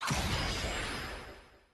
sound_transformation.mp3